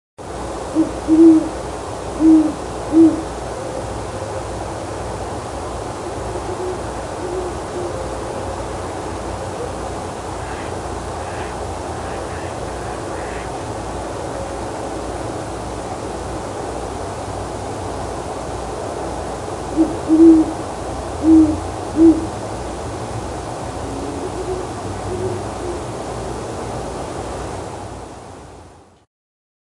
描述：明尼苏达州新布莱顿市的猫头鹰在深夜的树上。 在距离麦克风约40英尺的树上，可以看到猫头鹰的栖息地。 远处的猫头鹰对近处的猫头鹰有反应如果你仔细听就能听到。 在猫头鹰的叫声之间，可以听到遥远的鸭子。 用不知名的电容式话筒（不记得是哪种）直接录入Tascam US122接口。
Tag: 鸭子 叫声 猫头鹰 嘎嘎 冬季